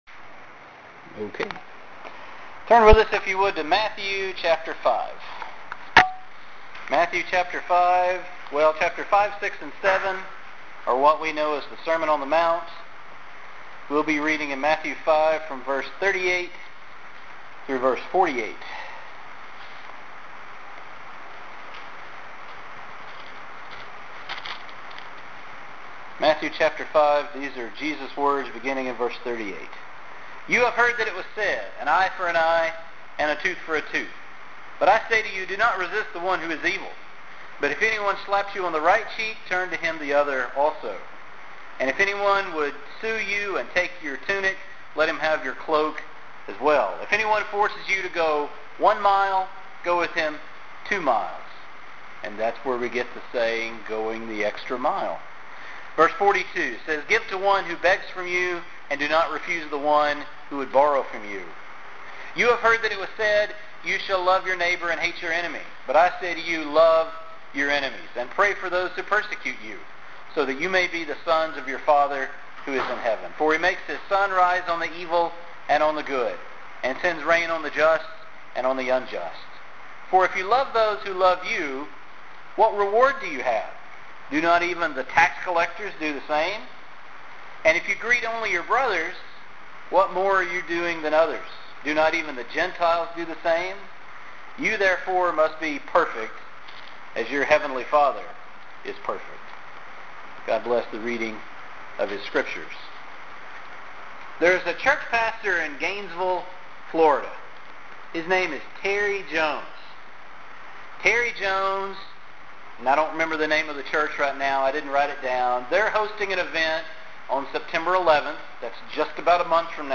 I have worked those ideas into a sermon, with more focus on exactly what Jesus would do and why.